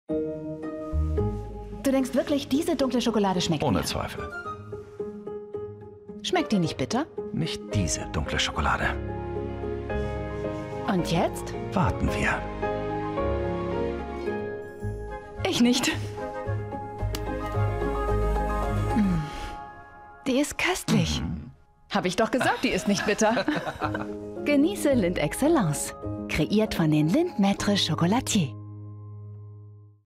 Commercieel, Toegankelijk, Warm, Zacht, Zakelijk